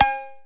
Perc [$$$].wav